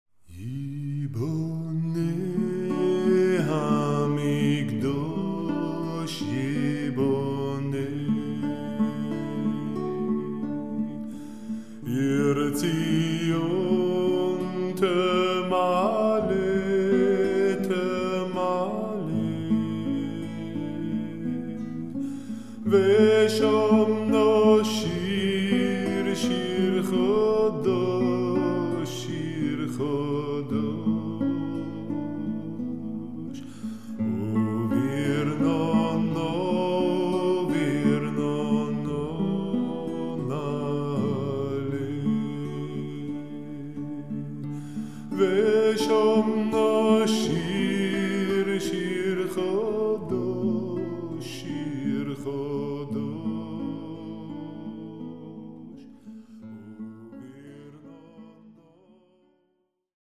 Shop / CDs / Vokal
jüdische Lieder